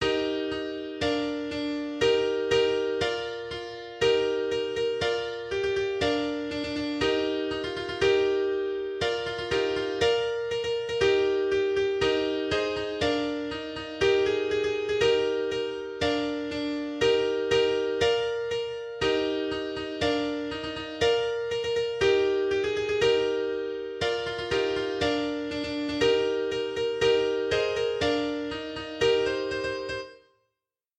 Those that are just a piano are the MIDI processed through LMMS without any fiddling with the instruments.
MP3 (which, with 2/4 timing obviously isn’t a waltz)